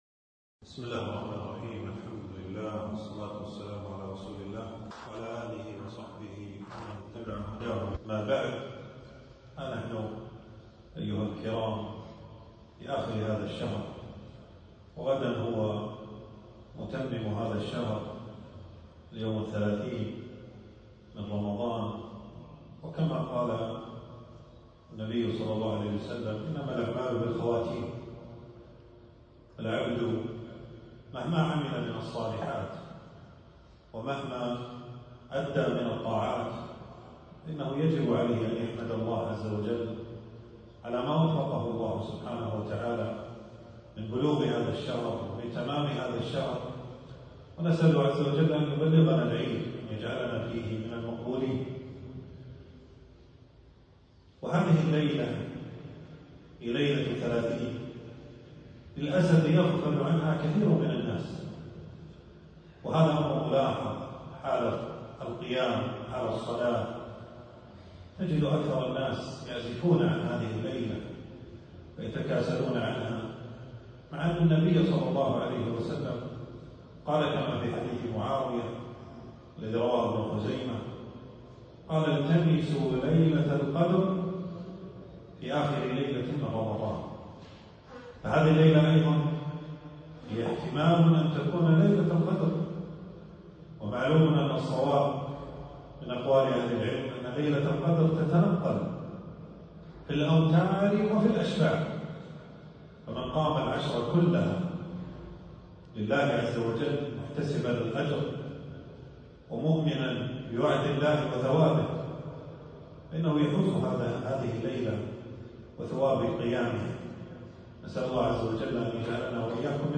تنزيل تنزيل التفريغ محاضرة بعنوان: كلمة في ختام شهر رمضان 1447هـ.
في مسجد أبي سلمة بن عبدالرحمن.